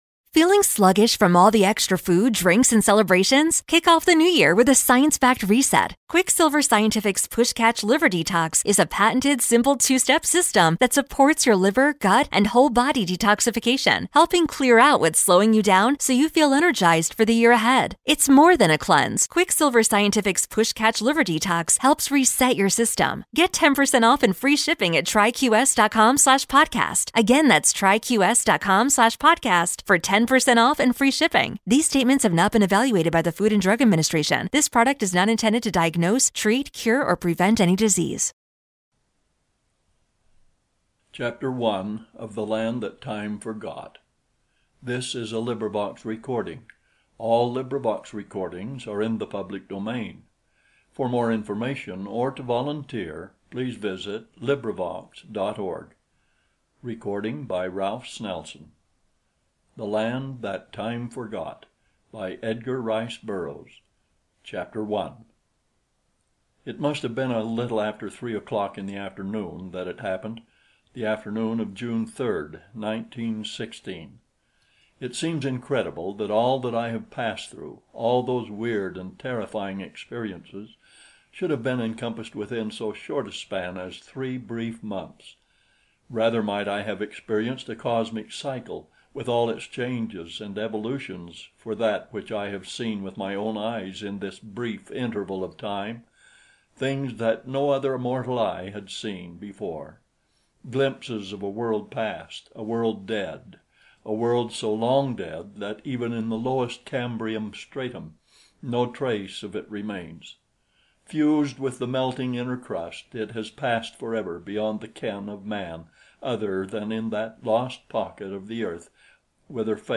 Great Audiobooks The Land that Time Forgot, by Edgar Rice Burroughs.